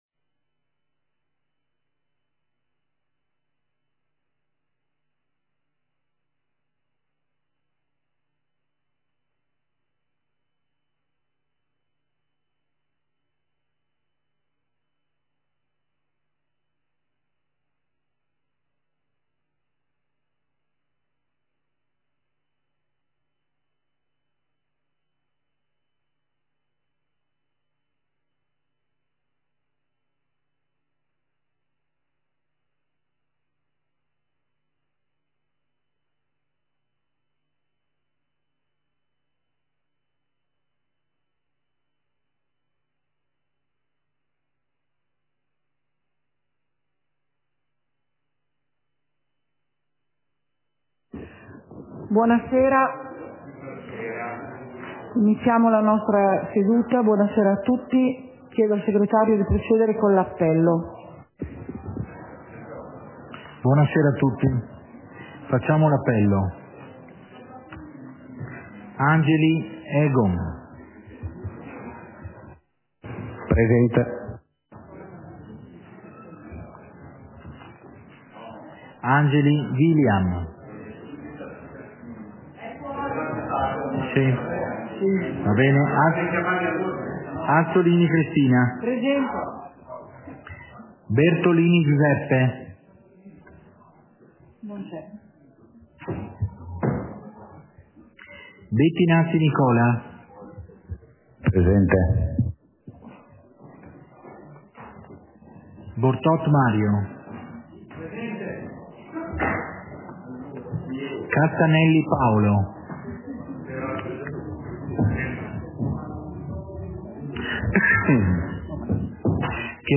Seduta del consiglio comunale - 27.09.2022